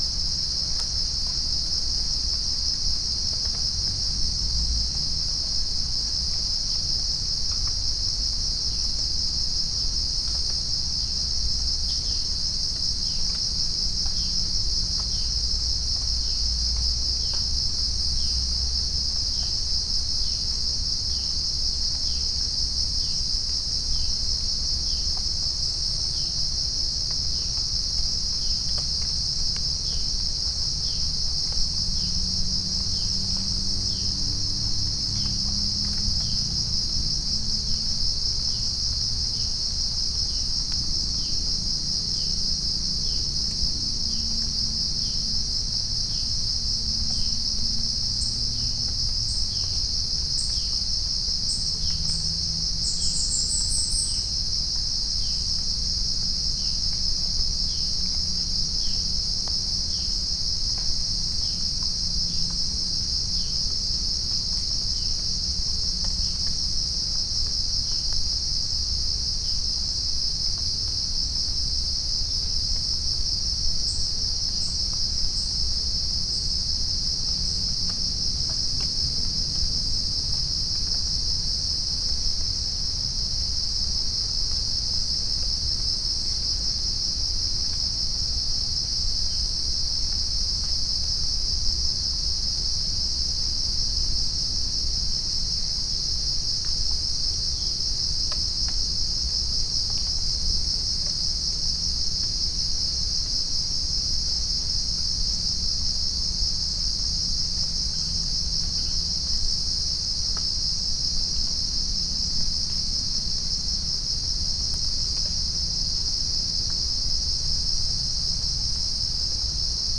Chalcophaps indica
Pycnonotus goiavier
Pycnonotus aurigaster
Orthotomus ruficeps
Halcyon smyrnensis
Dicaeum trigonostigma